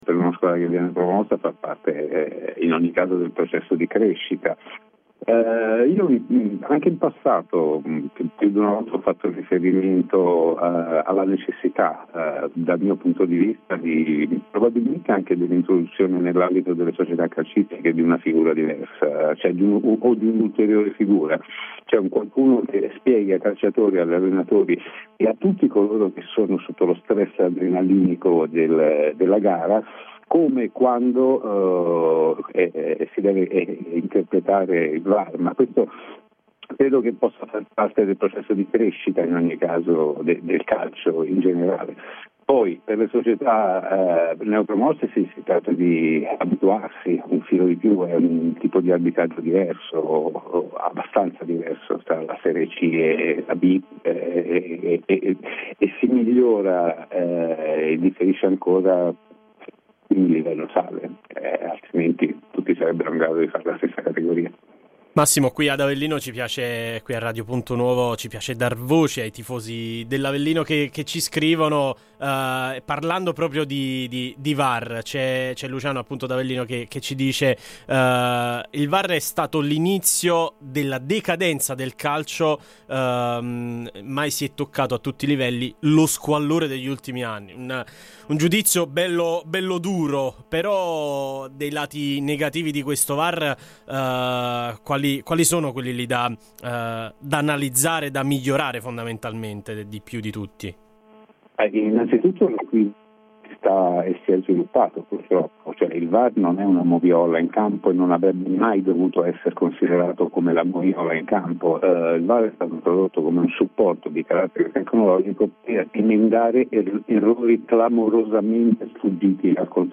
Ospite de Il Pomeriggio da Supereroi di Radio Punto Nuovo